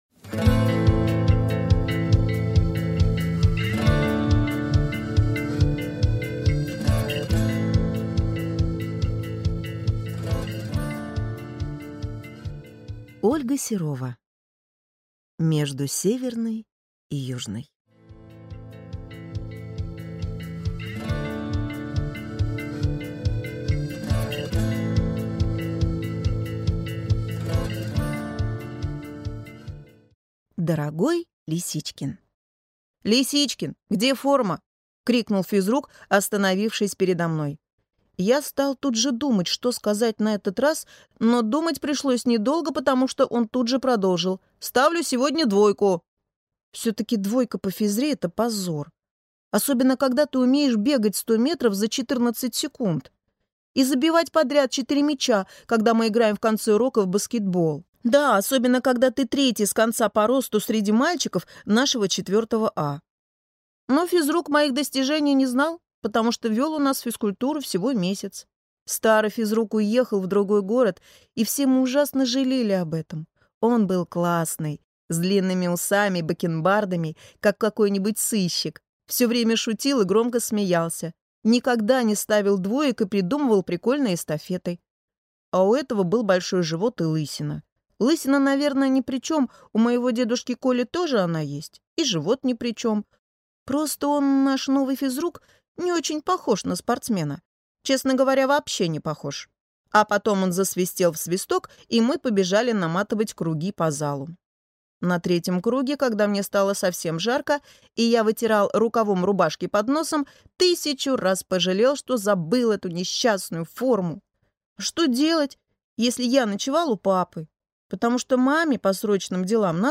Аудиокнига Между Северной и Южной | Библиотека аудиокниг